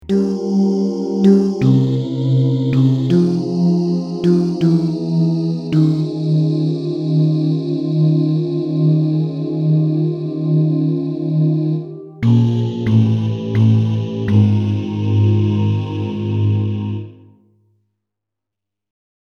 Key written in: F Minor
Type: Other mixed
Comments: Take this at a nice easy ballad tempo.